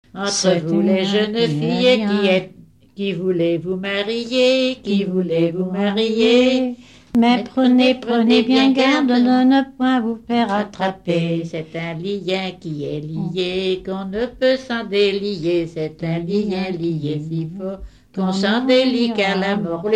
Note incomplète, en duo avec
Genre laisse
Pièce musicale inédite